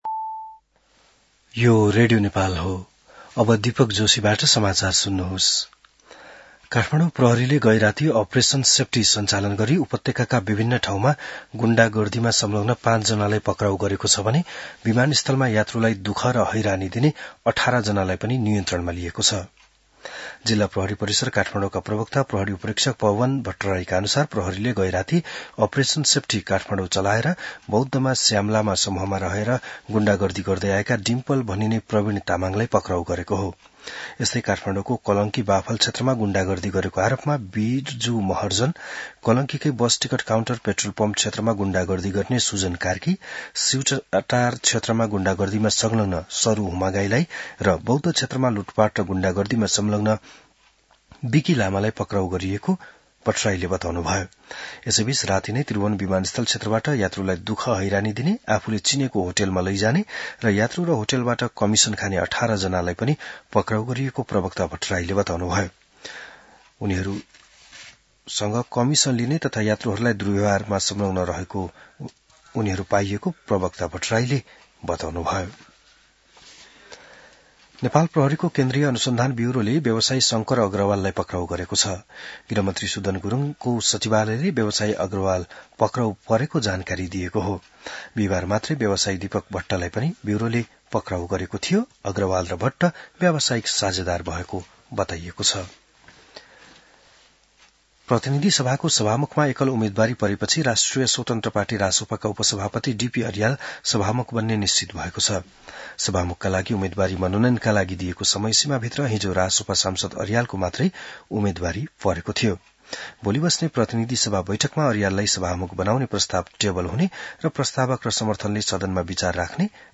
An online outlet of Nepal's national radio broadcaster
बिहान १० बजेको नेपाली समाचार : २१ चैत , २०८२